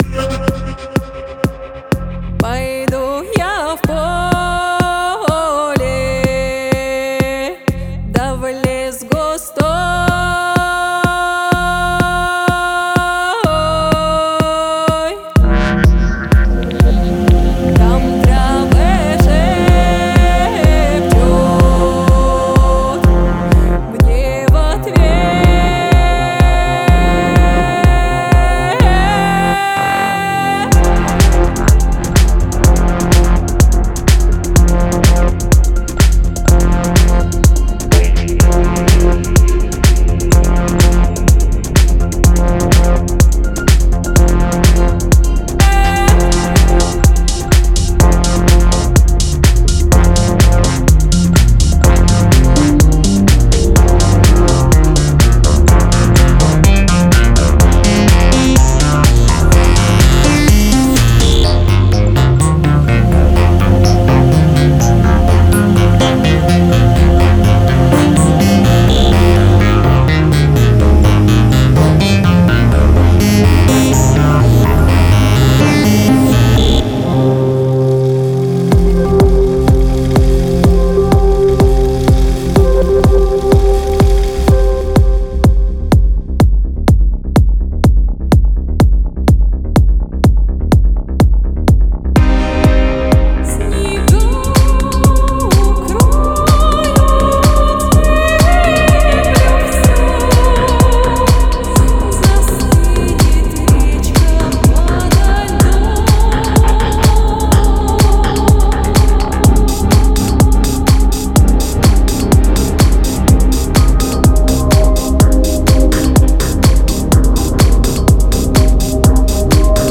Genre:Melodic Techno
まず惹きつけられるのは、心に響く美しさを持つスラブ系女性ボーカルループ。
そこで、深く唸るようなベースを収録しました。
そして、リスナーを別次元に連れて行くような濃密で空間的なパッド。
さらに、空を駆けるようなリードシンセ、きらめくプラック音、そして胸の奥を揺さぶる感情的なコードを収録しています。